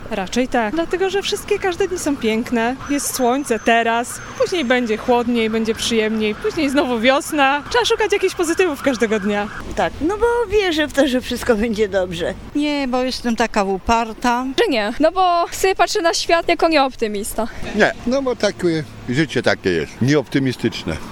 Mieszkańców Stargardu zapytaliśmy, czy uważają się za optymistów.